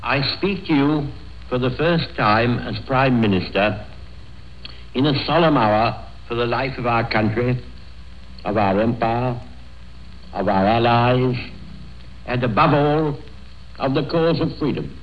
Ecouter son bla, bla, bla ?1940Premier discours de Winston CHURCHILL à la radio.